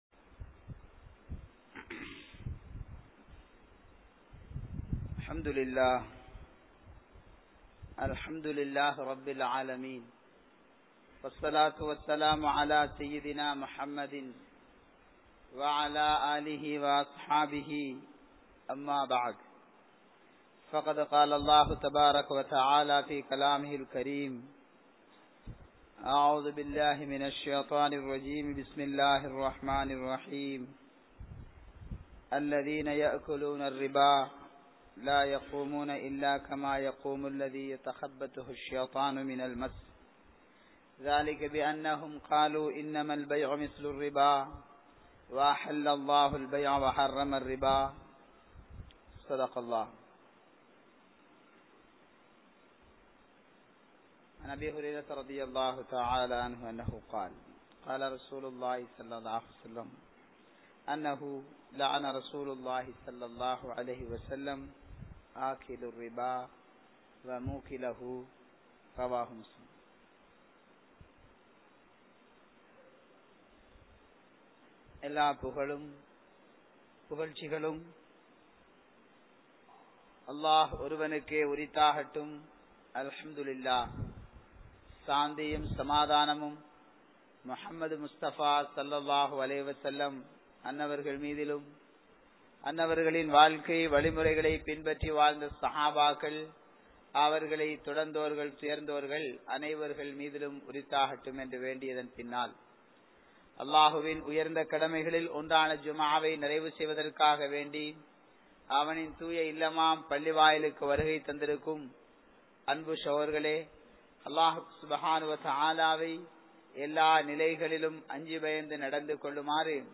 Vatti(Riba) | Audio Bayans | All Ceylon Muslim Youth Community | Addalaichenai